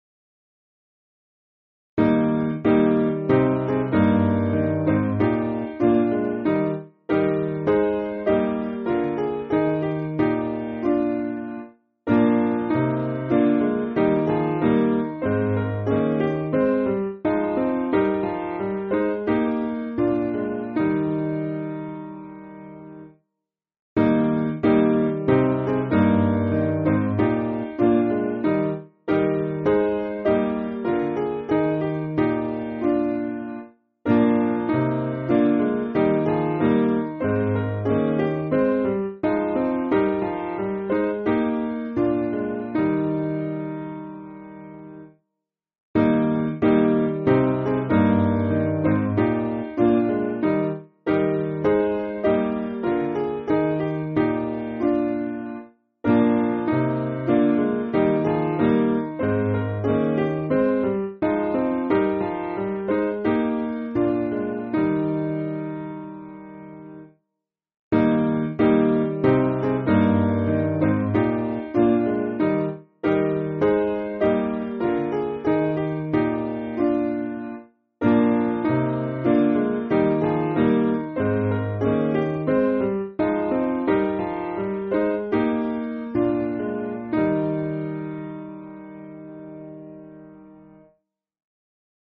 Simple Piano
(CM)   4/Eb